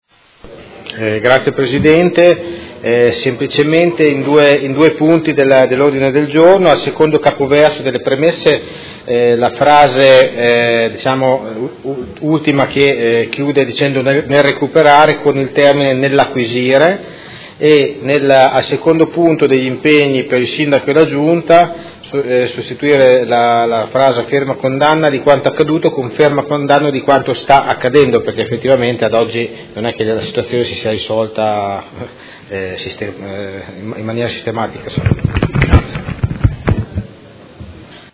Seduta del 26/03/2026 Presenta emendamento nr. 45122 a Ordine del Giorno presentato dai Consiglieri Fasano, Poggi, Morini, Venturelli, Forghieri, Pacchioni, Bortolamasi, Carpentieri, Baracchi, De Lillo, Lenzini, Liotti e Arletti (PD) avente per oggetto: Solidarietà alla popolazione di Afrin e ai civili vittime di violenza
Audio Consiglio Comunale